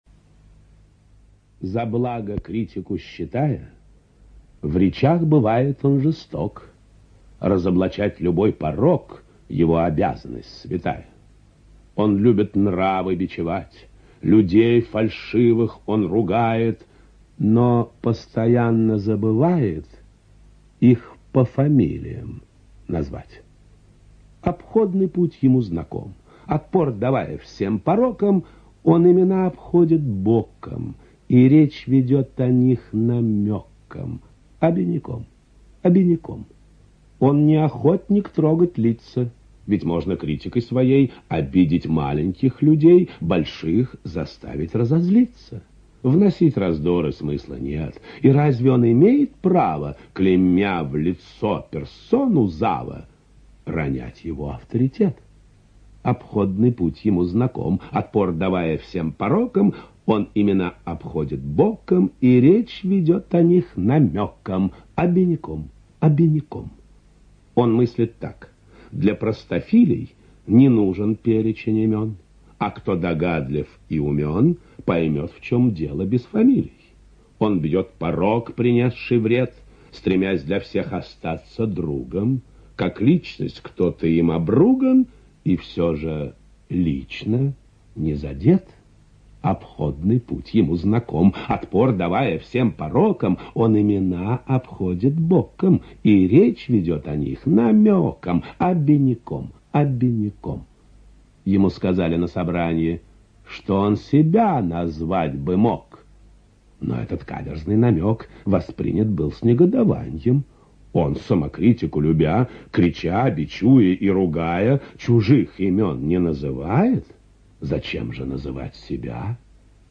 ЧитаетШалевич В.
ЖанрПоэзия